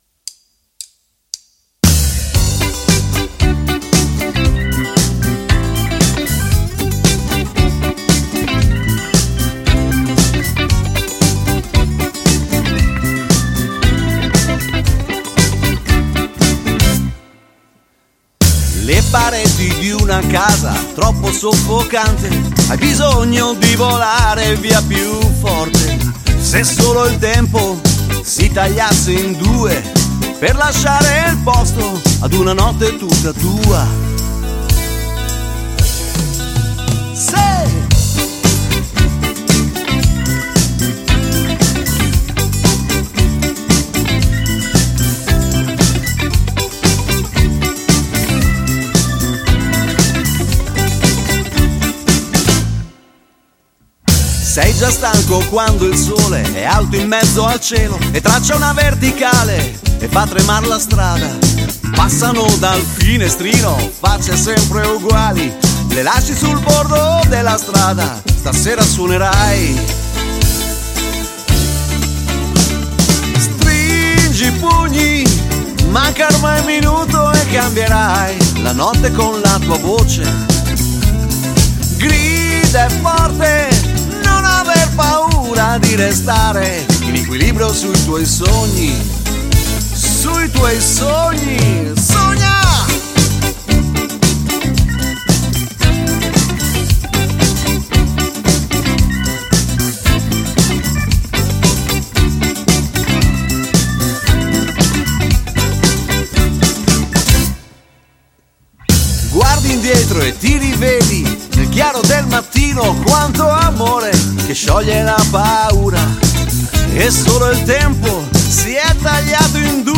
Luogo esecuzioneDuna Studio - Ravenna
GenerePop